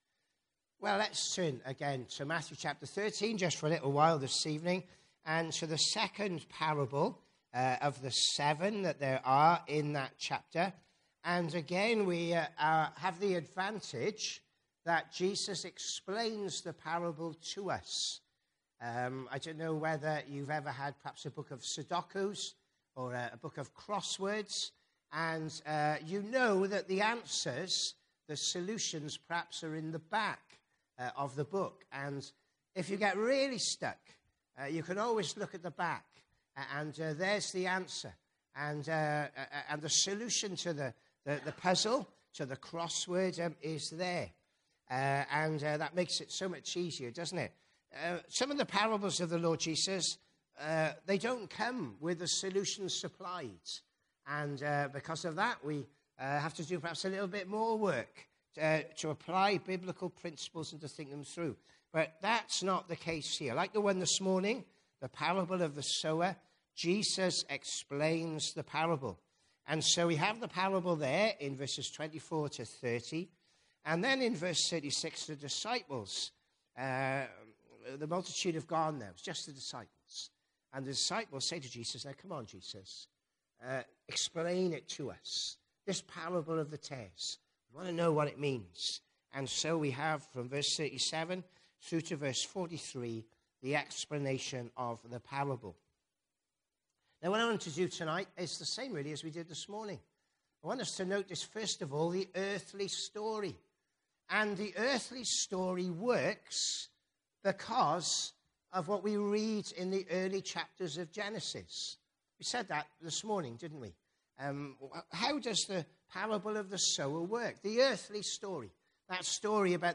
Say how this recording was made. Passage: Psalm 65:1-13, Matthew 13:24-43 Congregation: PM Service